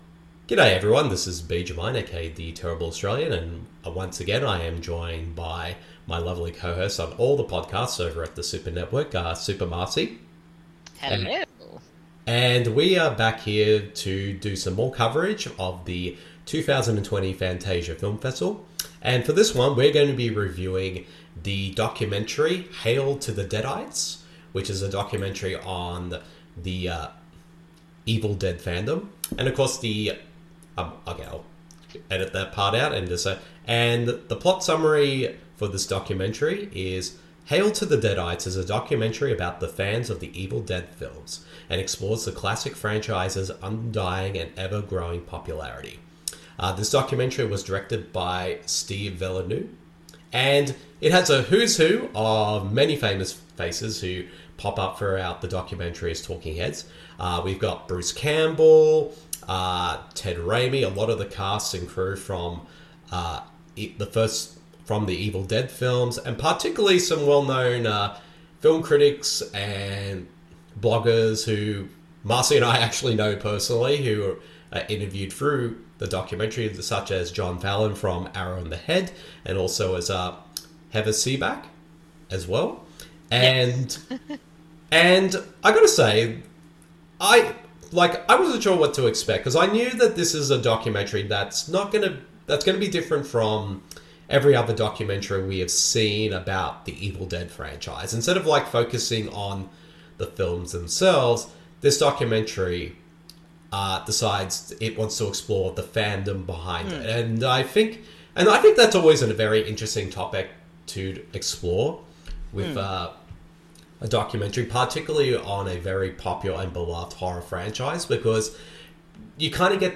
[Fantasia 2020] Audio Review: Hail to the Deadites (2020)
The following review of the film is in an audio format.